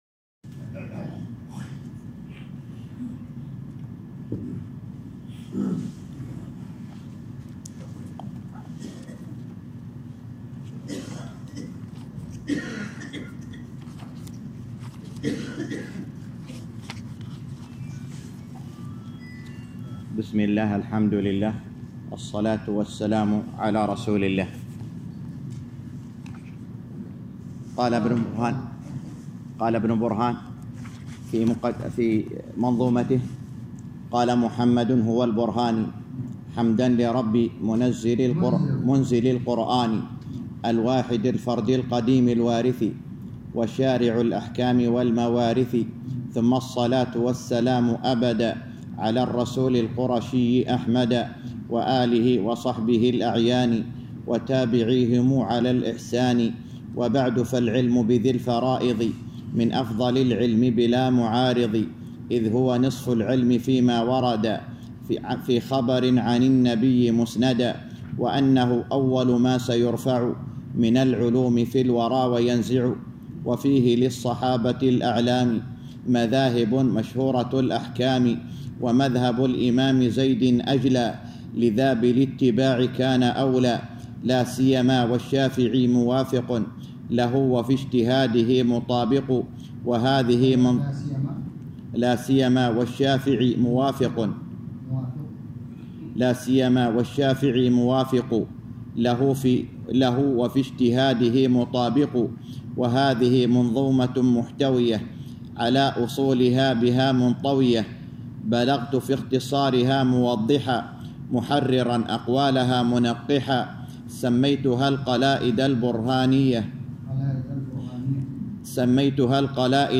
الدرس الثاني - شرح المنظومة البرهانية في الفرائض _ 2